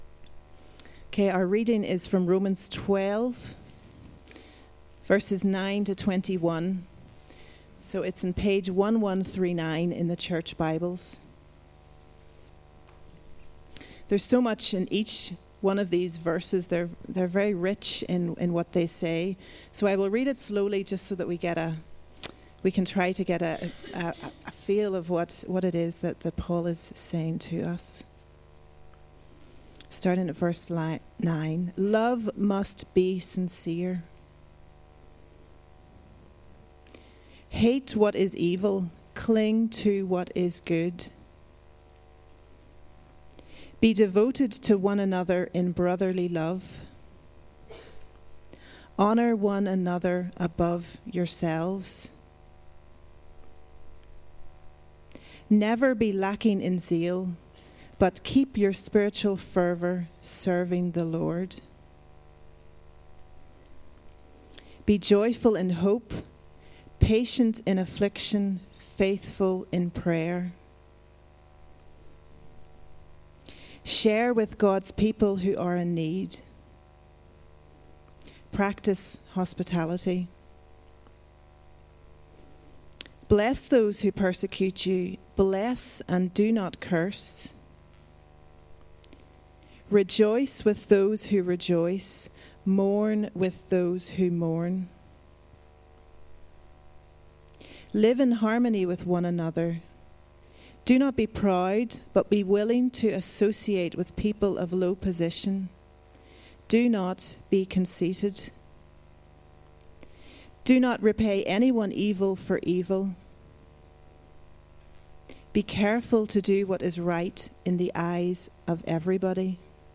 Media for Sunday Service on Sun 15th Feb 2015 10:00
Theme: Sermon